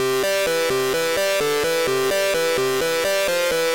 描述：我在Sylenth 1中快速制作的漂亮的机器人引线（。
Tag: 128 bpm House Loops Synth Loops 647.03 KB wav Key : Unknown